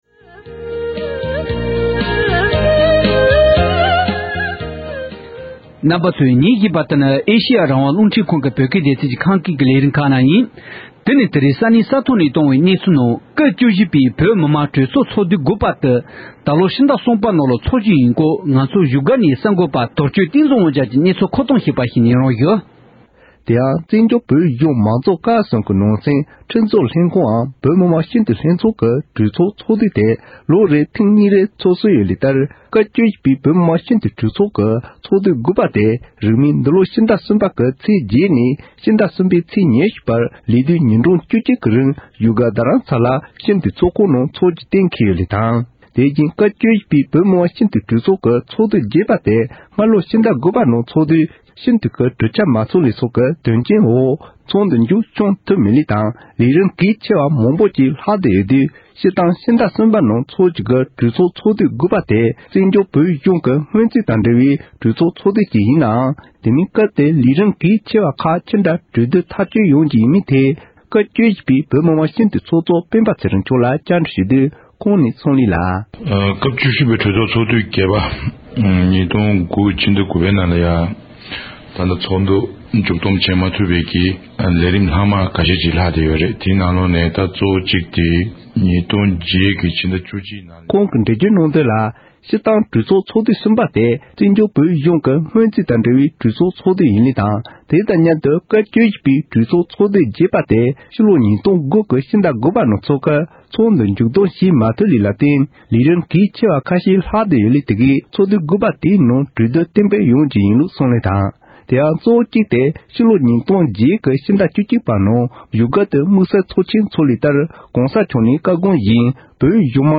བོད་མི་མང་སྤྱི་འཐུས་ཚོགས་གཙོ་སྤེན་པ་ཚེ་རིང་ལགས་སུ་བཀའ་འདྲི་ཞུས་པར་གསན་རོགས